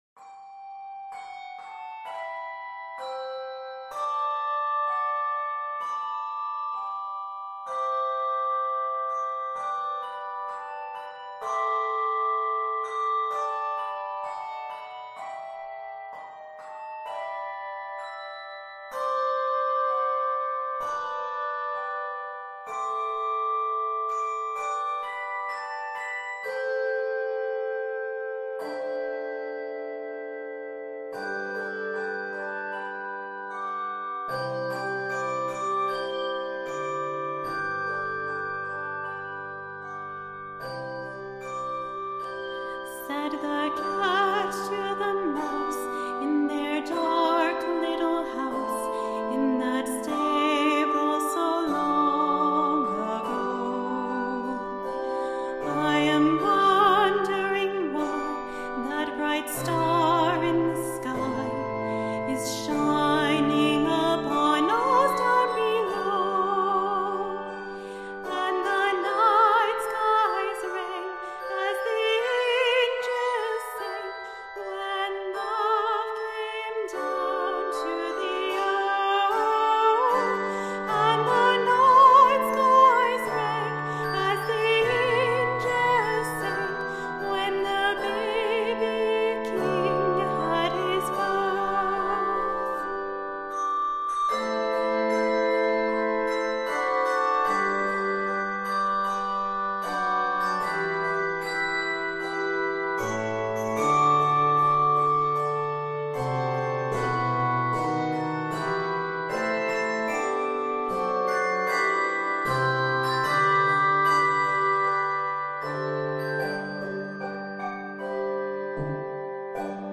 for handbells with vocal solo
Begins in G Major, then modulates to Ab Major. 98 measures.